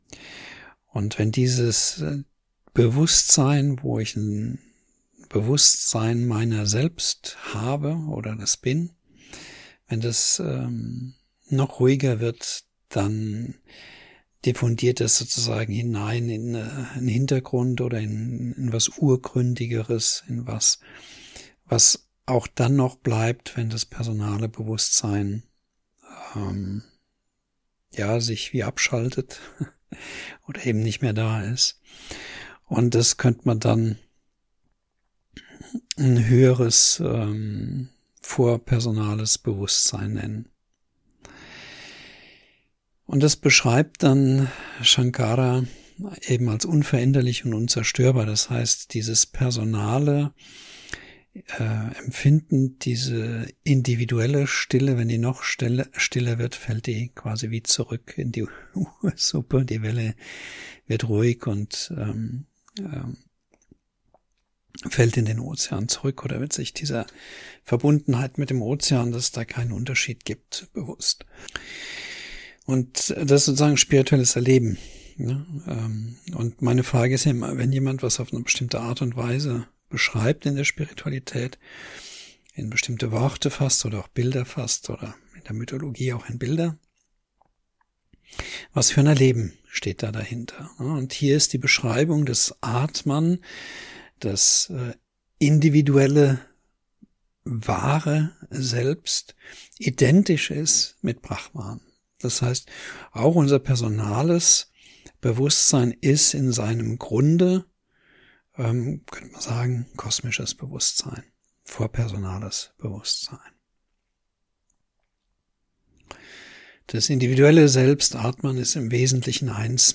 03 Atman und Advaita Vedanta (Vortrag)    20min